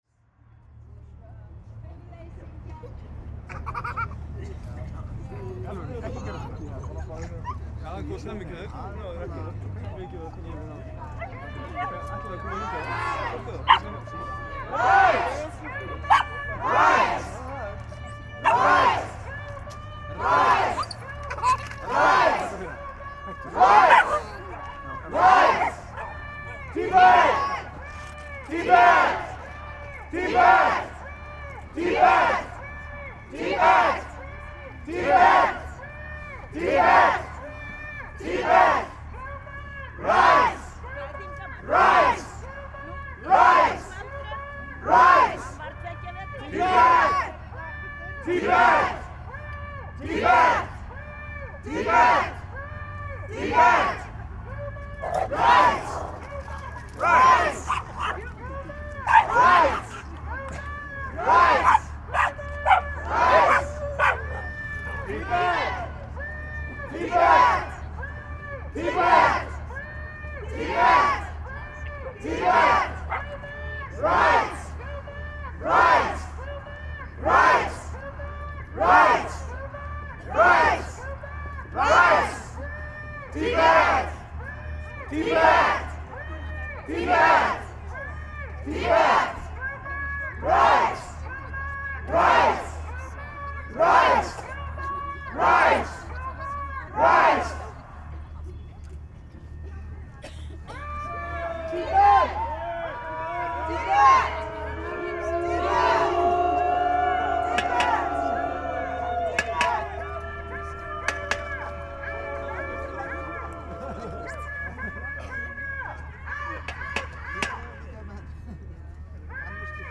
In the afternoon at 22 of December 2011, I put hydrophone in the pool and recorded more than one hour of mystique sound. Most audible sound are rumbling sound from the surf. Sometimes the waves goes all the way in the pool with splashing sound and sometimes footsteps can be heard when people walk by the pool side. But there is also many other sounds from organic creatures, most likely from hungry snails scratching the pool surface.
Mic: Aquarian H2a-XLR